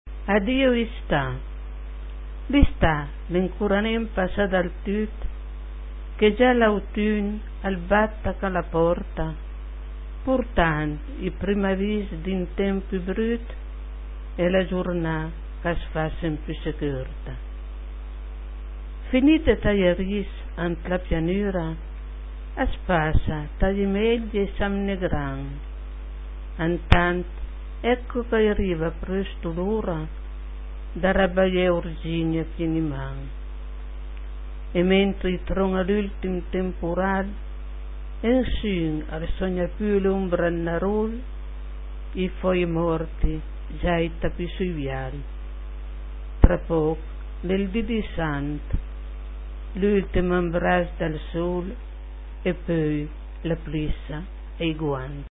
Cliché chi sùta par sénti la puizìa recità da l'autùr ...